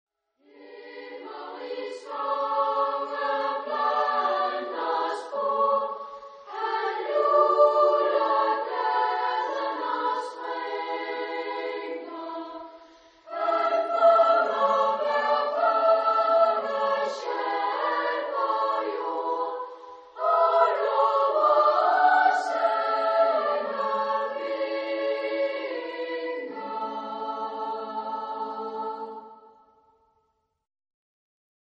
Género/Estilo/Forma: Canción de Navidad ; Homófono
Carácter de la pieza : melodioso ; cantabile ; alegre
Tipo de formación coral: SSA  (3 voces Coro infantil O Coro femenino )
Tonalidad : la mayor